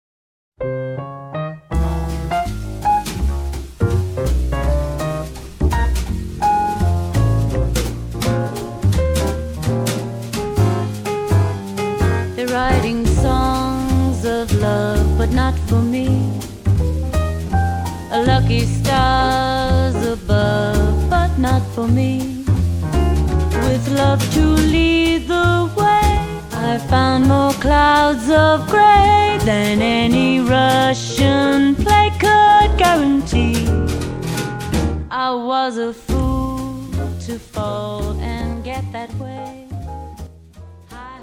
Piano
Double Bass
Guitar
Drums